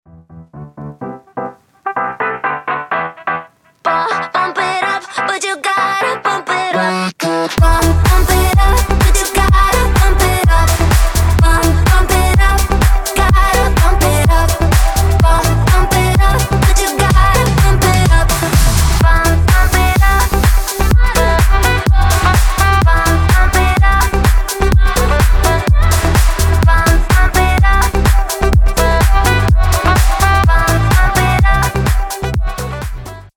• Качество: 320, Stereo
зажигательные
веселые
house
динамичные